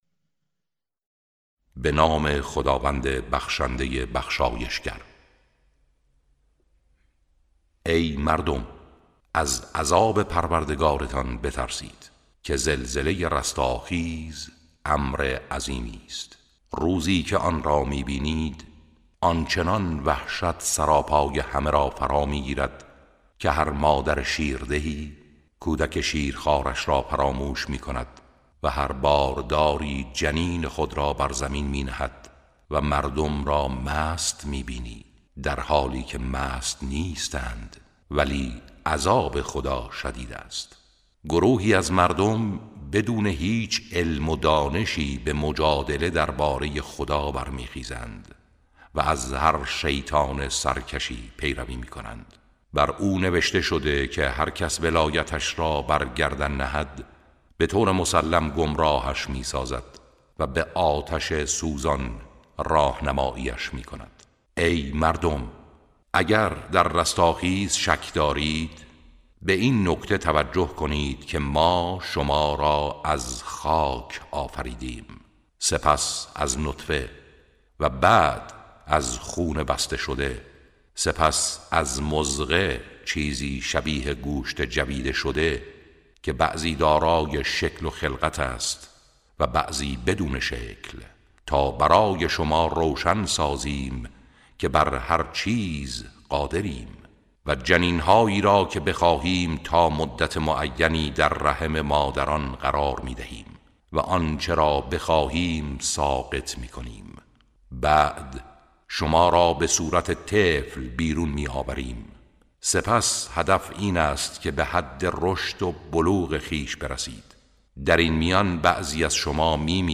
ترتیل صفحه ۳۳۲ سوره مبارکه حج(جزء هفدهم)
ترتیل سوره( حج)